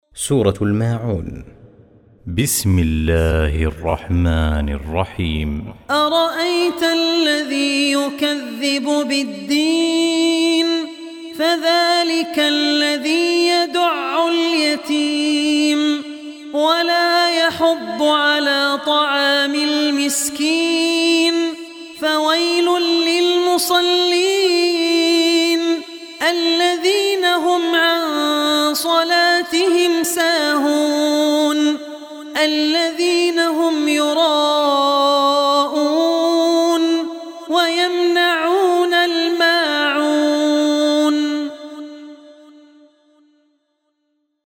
Surah Maun Audio Recitation
107-surah-maun.mp3